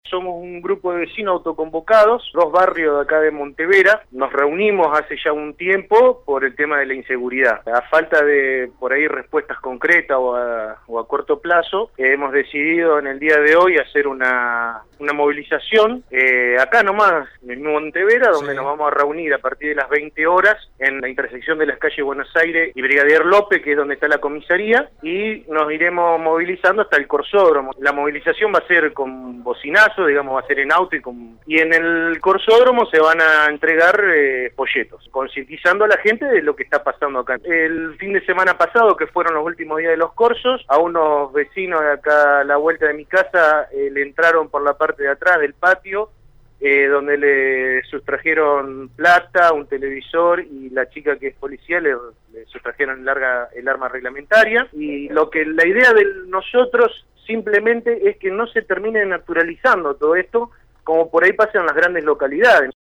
«Somos un grupo de vecinos autoconvocados de Monte Vera, dosbarrios nos unimos hace unos años contra la inseguridad», señaló un vecino a Radio EME.